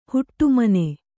♪ huṭṭu mane